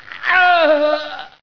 scream5.ogg